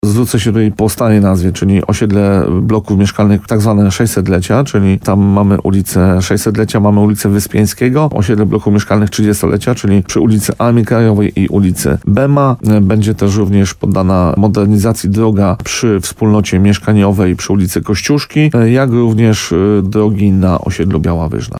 Jak mówił w programie Słowo za Słowo w radiu RDN Nowy Sącz burmistrz Grybowa Paweł Fyda, priorytetem w inwestycjach jest modernizacja dróg na większych osiedlach, na co już pozyskano zewnętrzne dotacje.